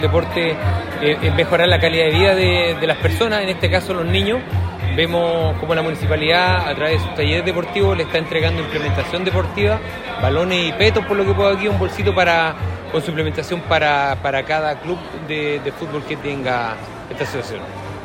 Por su parte, el concejal, Bruno Hauenstein, señaló que, “el deporte es mejorar la calidad de vida de los niños, y hoy vemos, como la municipalidad, a través, de estos talleres deportivos puedan recibir la implementación necesaria para seguir mejorando”.
Bruno-Hauenstein.mp3